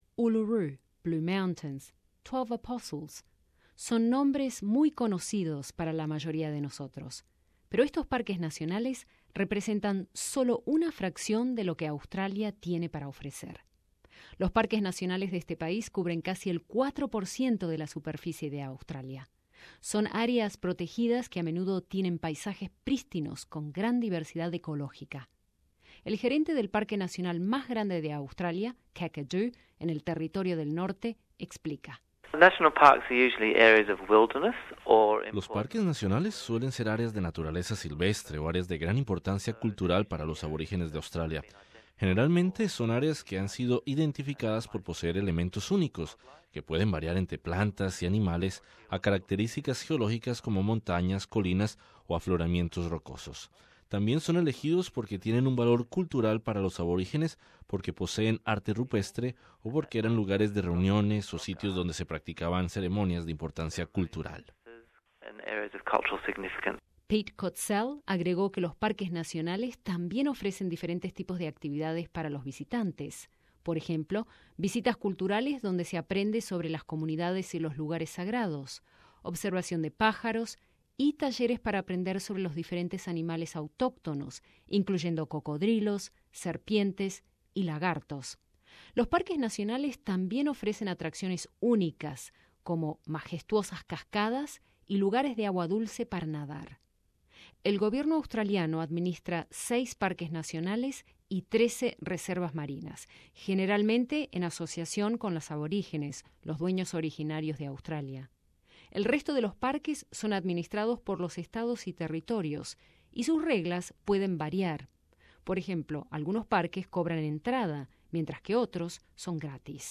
Escucha este podcast que incluye una entrevista